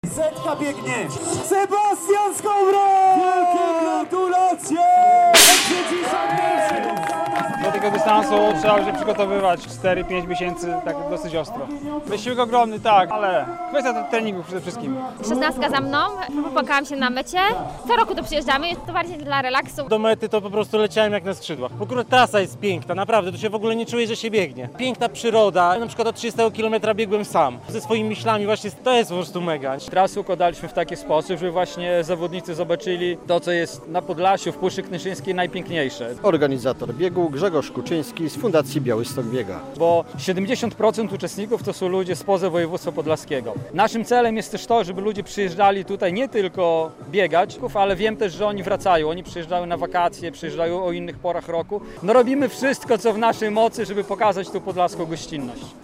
Bison Ultra Trail - relacja